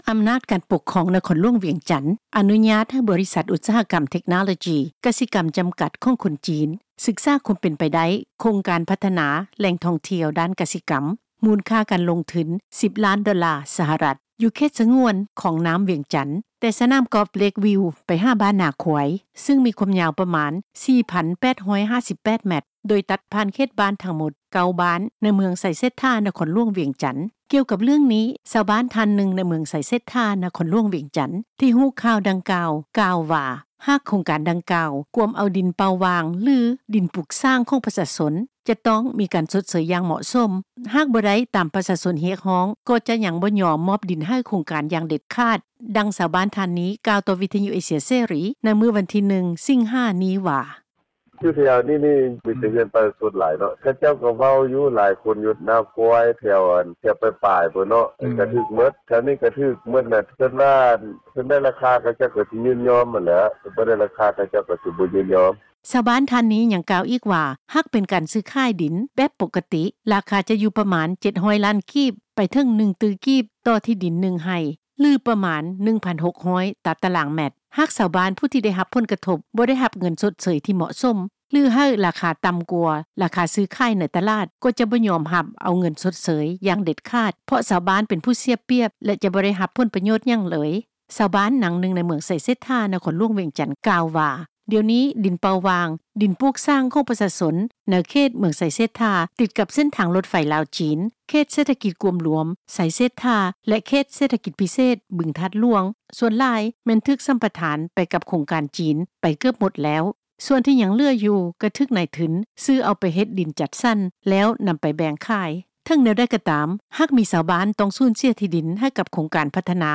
ດັ່ງ ຊາວບ້ານ ທ່ານນີ້ ກ່າວຕໍ່ ວິທຍຸ ເອເຊັຍເສຣີ ໃນມື້ວັນທີ 1 ສິງຫາ ນີ້ວ່າ:
ຜູ້ປະກອບການ ຮ້ານອາຫານ ແຫ່ງໜຶ່ງໃນເມືອງໄຊເສດຖາ ນະຄອນຫລວງວຽງຈັນ ກ່າວວ່າ ຍັງບໍ່ທັນຮັບຮູ້ເທື່ອວ່າ ຈະມີນັກລົງທຶນຈີນ ເຂົ້າມາພັດທະນາ ໂຄງການທ່ອງທ່ຽວ ດ້ານກະສິກໍາ ຢູ່ເຂດສະງວນ ຄອງນ້ໍາວຽງຈັນ ແຕ່ໄລຍະທີ່ຜ່ານມາ ກະເຄີຍໄດ້ຍິນຂ່າວວ່າ ດິນໃນເຂດນີ້ ຈະຖືກສໍາປະທານ ໄປພັດທະນາ ໂຄງການທັງໝົດ. ດັ່ງ ຜູ້ປະກອບການ ຮ້ານອາຫານ ນາງນີ້ ກ່າວຕໍ່ ວິທຍຸ ເອເຊັຽເສຣີ ໃນມື້ດຽວກັນນີ້ວ່າ: